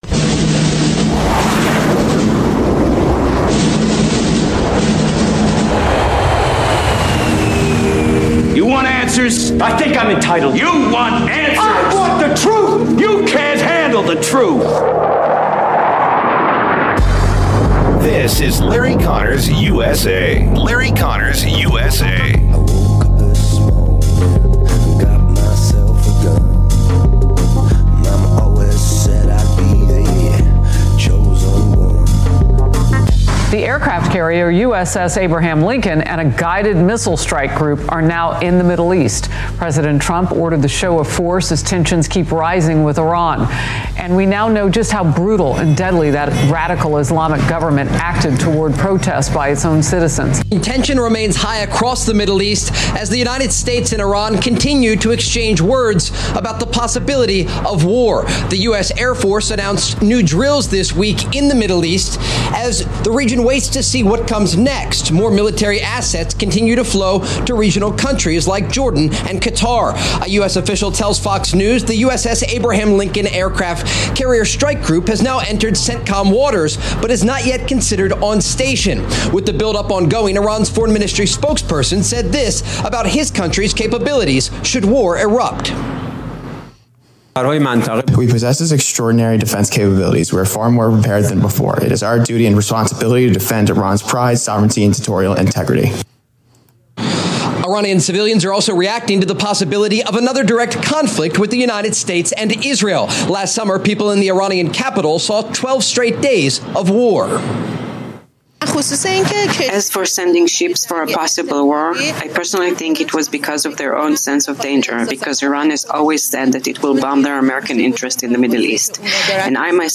Guest: Sidney Powell